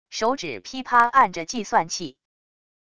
手指噼啪按着计算器wav音频